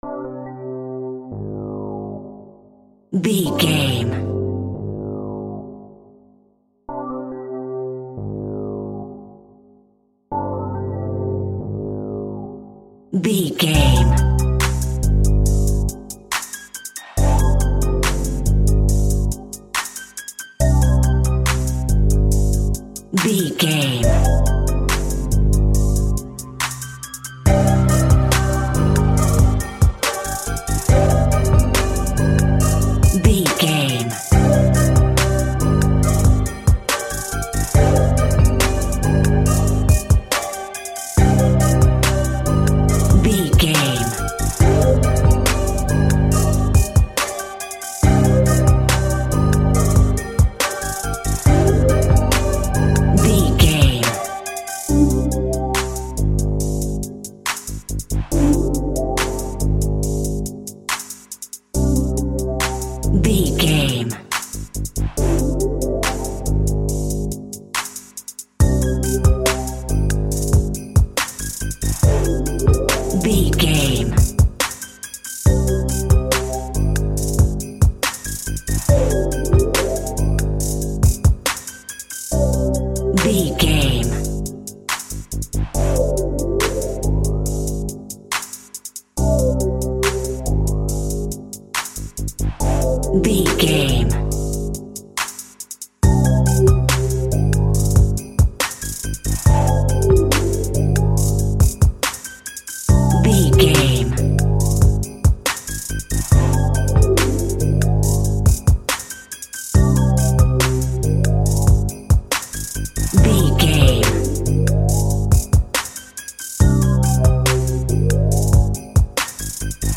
Rap Beat Instrumental Cue.
Aeolian/Minor
hip hop
chilled
laid back
hip hop drums
hip hop synths
piano
hip hop pads